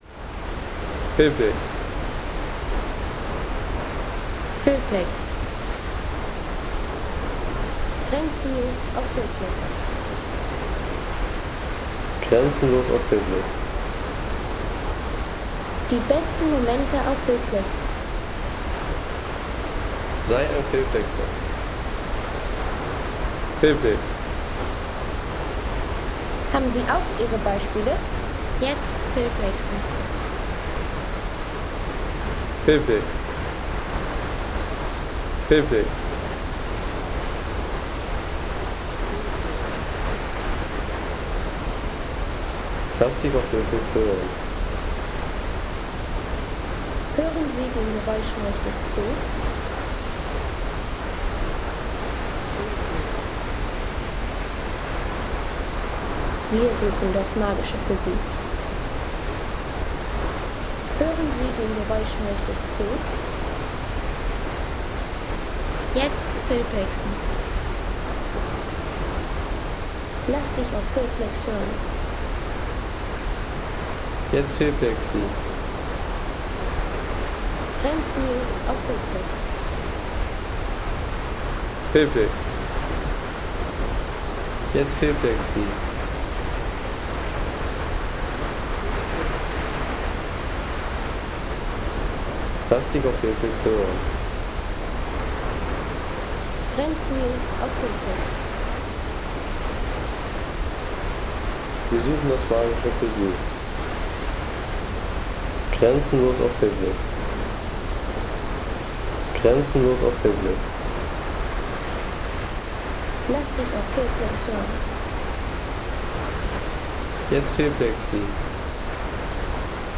Schlatenbach-Wasserfall, 1819m